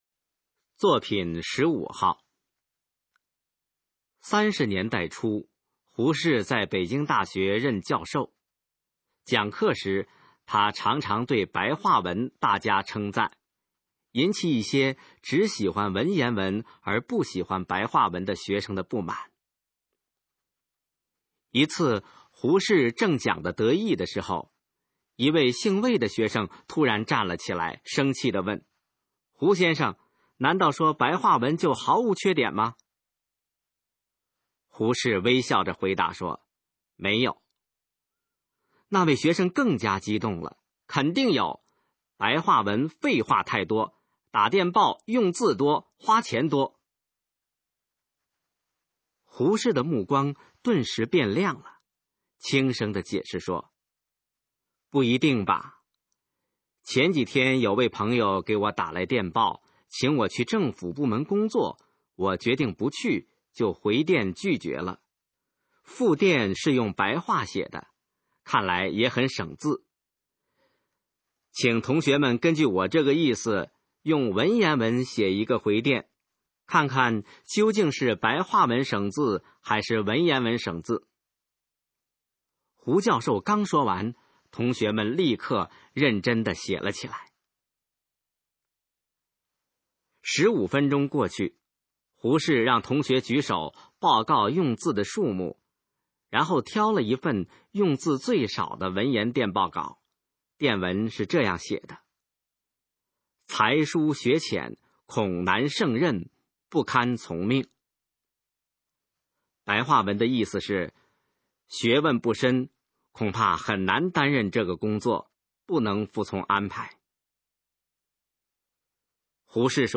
首页 视听 学说普通话 作品朗读（新大纲）
《胡适的白话电报》示范朗读_水平测试（等级考试）用60篇朗读作品范读